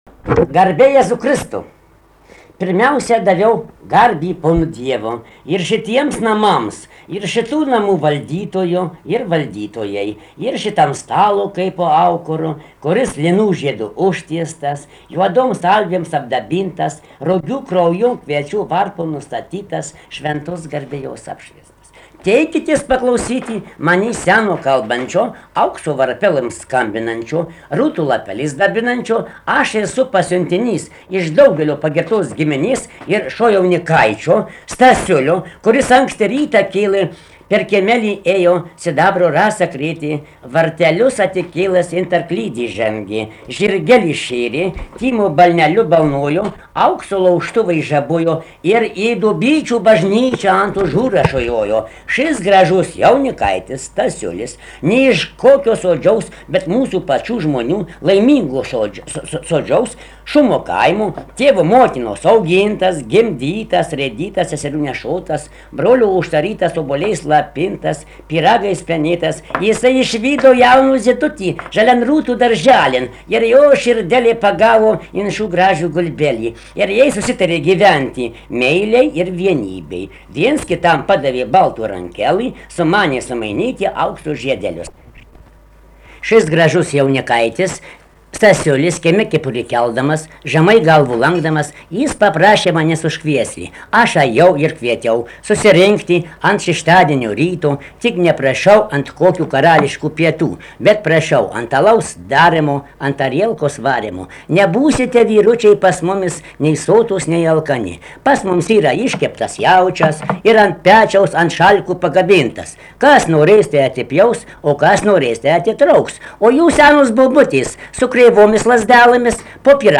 Kriokšlys
vokalinis